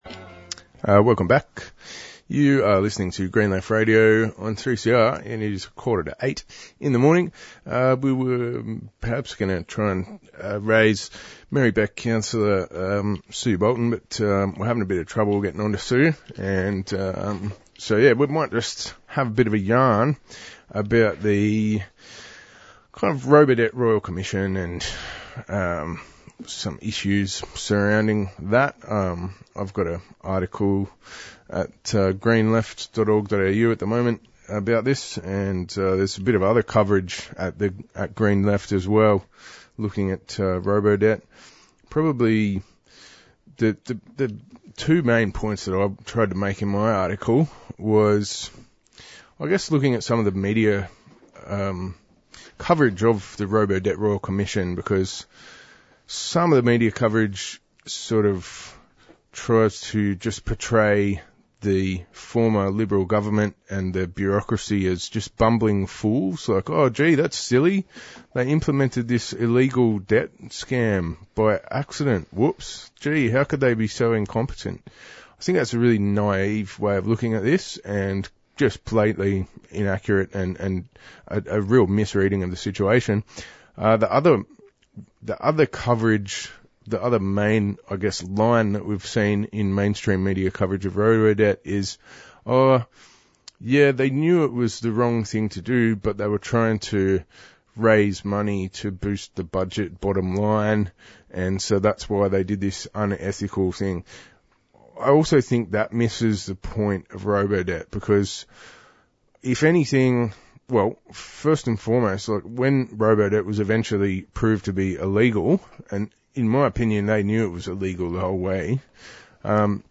Featuring interviews on the limitations of the ALP's recent refugee announcement, what was the political motivation behind Robodebt and how Turkish dictator President Recep Tayyip Erdoğan is using the earthquake disaster as a weapon in his ongoing war on the Kurdish people.